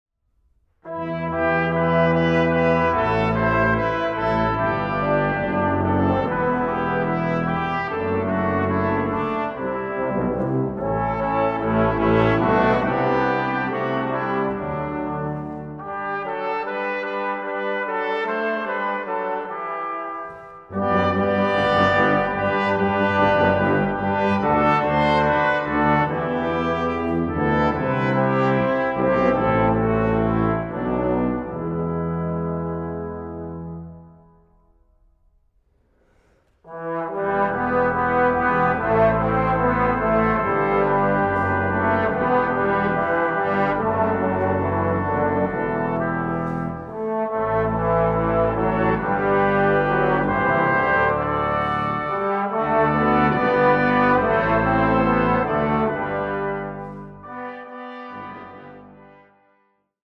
Aufnahme: Jesus-Christus-Kirche Berlin-Dahlem, 2011